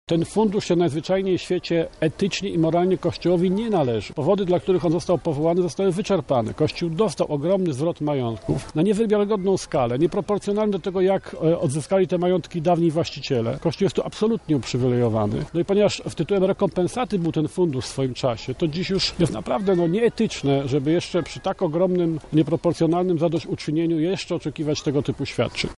O szczegółach mówi Janusz Palikot przewodniczący Twojego Ruchu